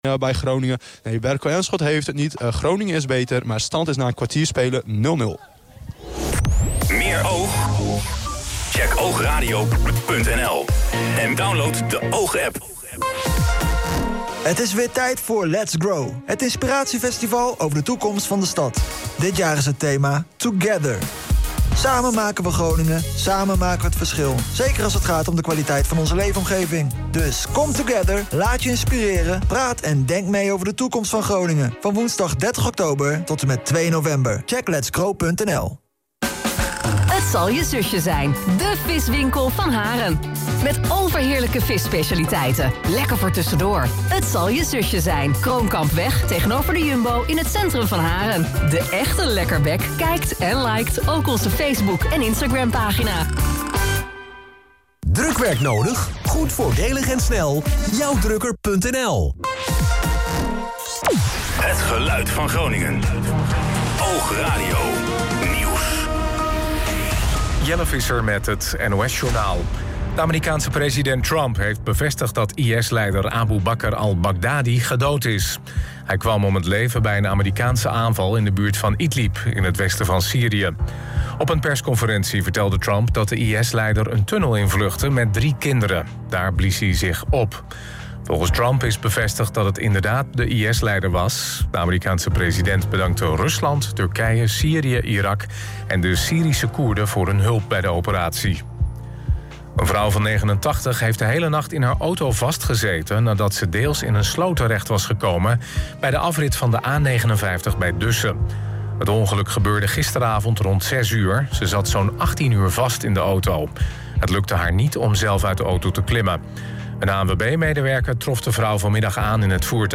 OOG Sport bracht zondag onder meer live verslagen van 2 voetbalwedstrijden en een hockeyduel.